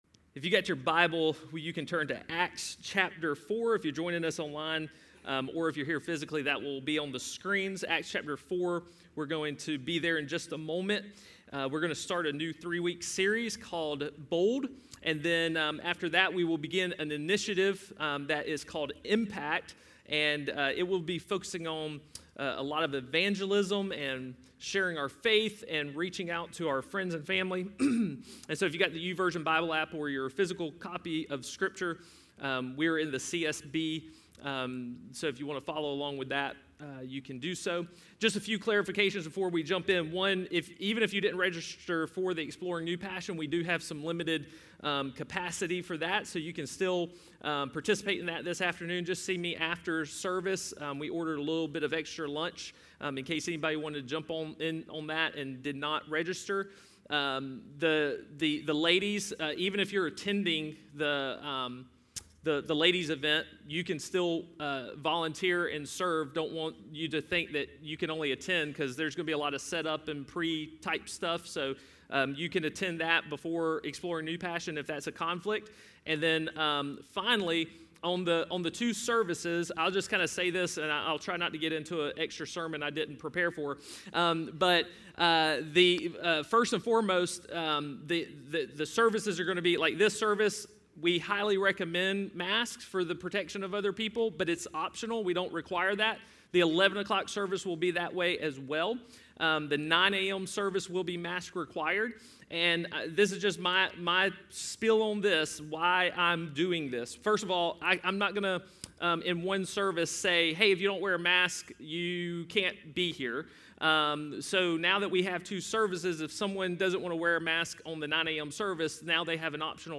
A message from the series "Bold."